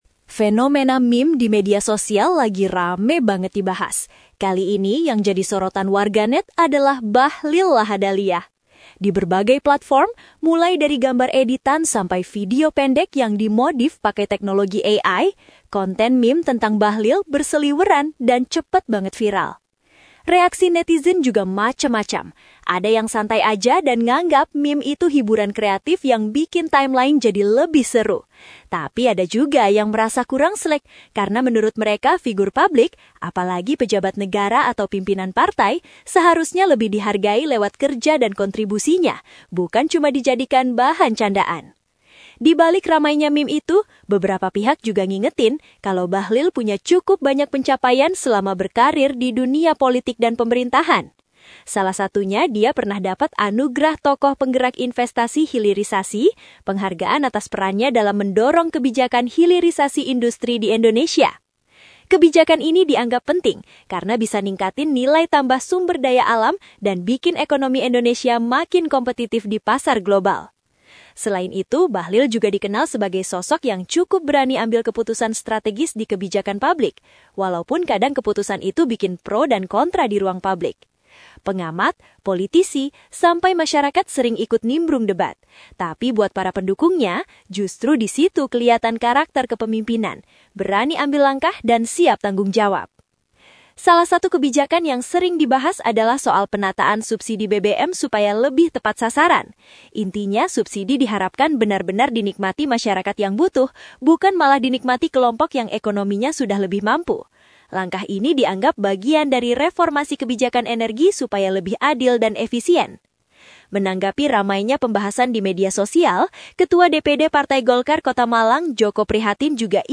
Narator Digital